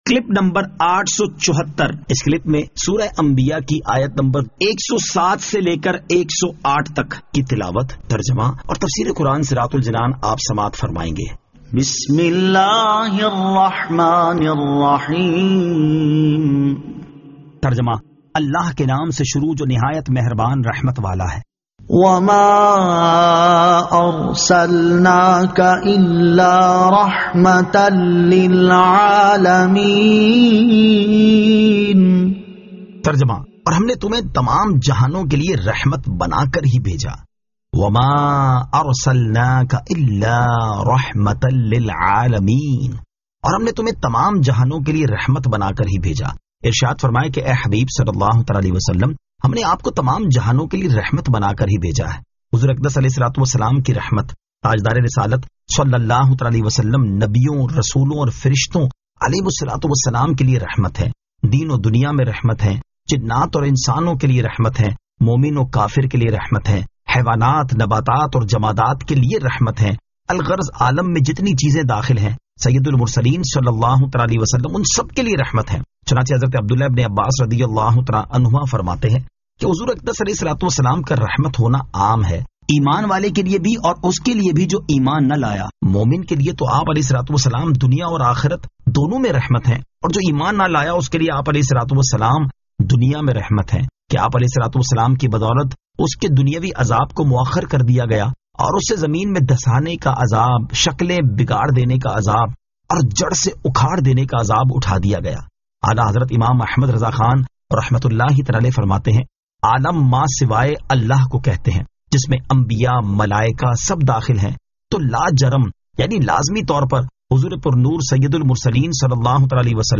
Surah Al-Anbiya 107 To 108 Tilawat , Tarjama , Tafseer